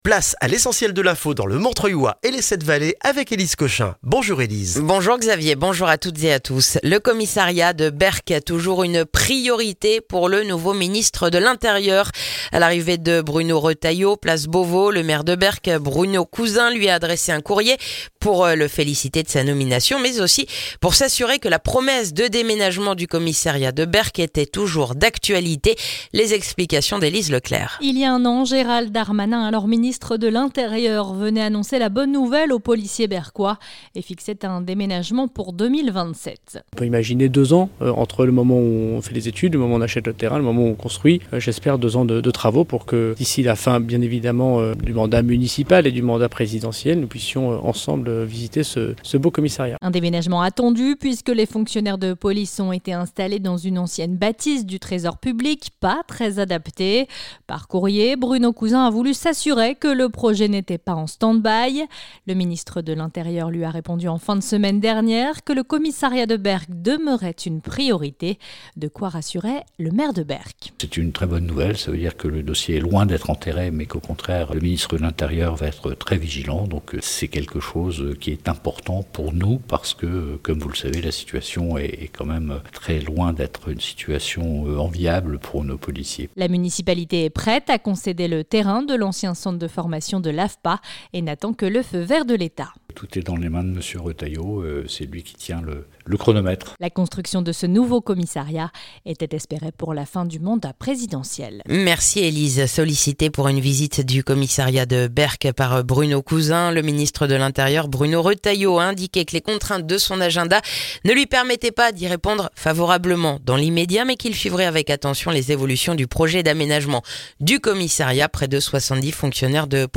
Le journal du mardi 15 octobre dans le montreuillois